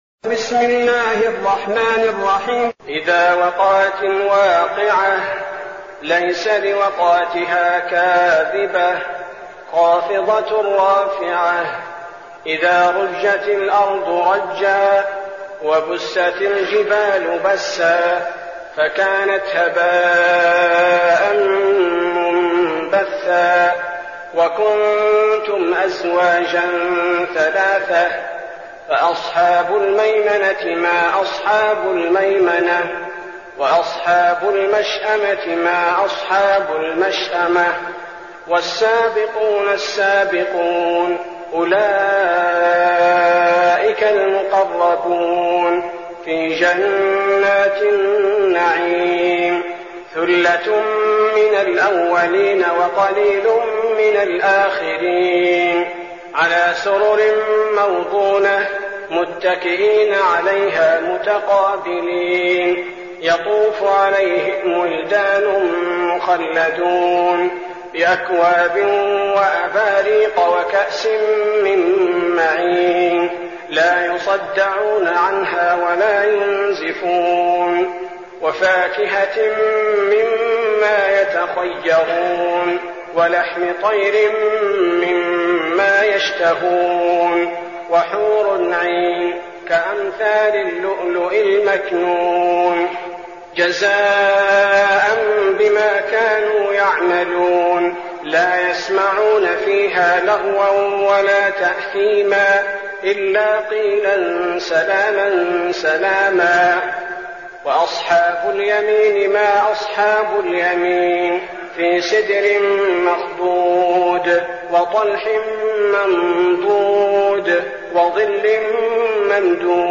المكان: المسجد النبوي الشيخ: فضيلة الشيخ عبدالباري الثبيتي فضيلة الشيخ عبدالباري الثبيتي الواقعة